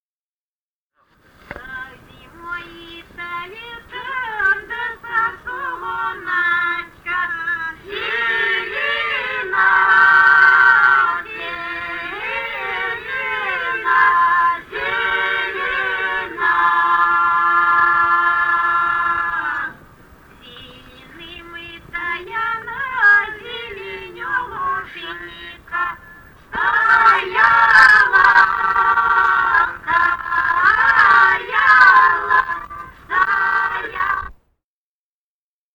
Записали участники экспедиции